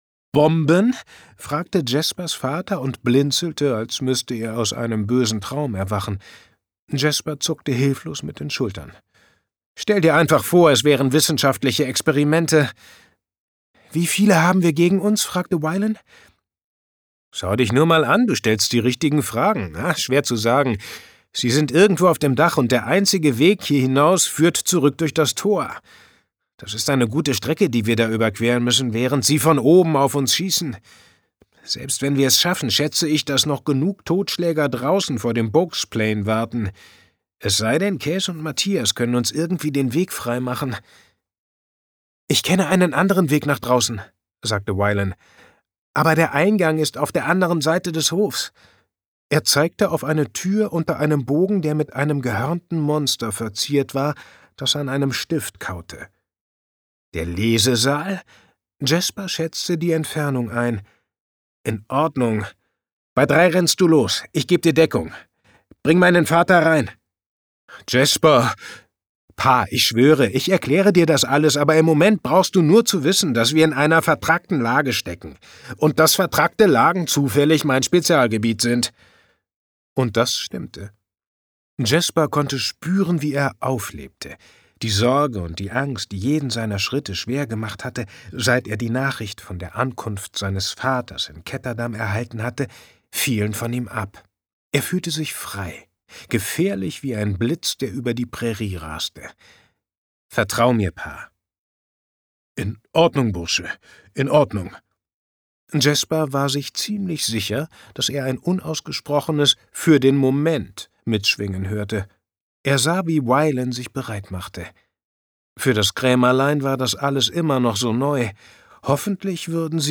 Schlagworte Erwachsene • Fantastik • Fantasy • Grischa • Hörbuch • Magie • Ungekürzt